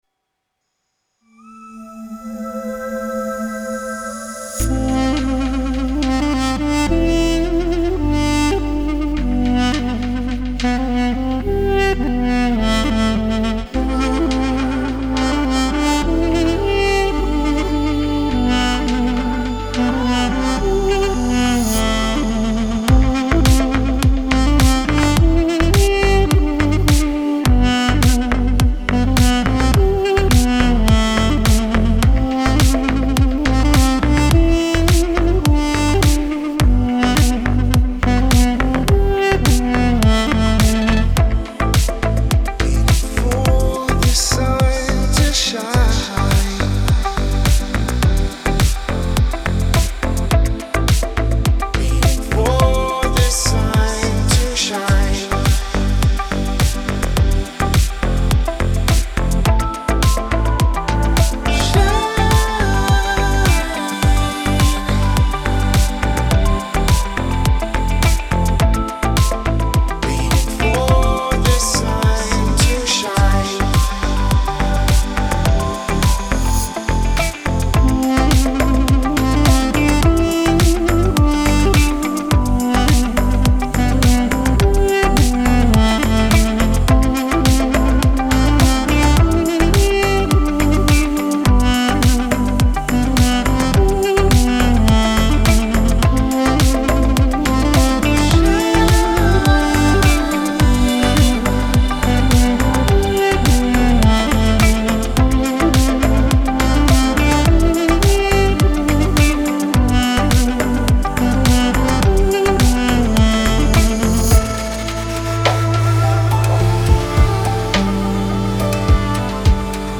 موسیقی کنار تو
سبک ریتمیک آرام , موسیقی بی کلام
موسیقی بی کلام اورینتال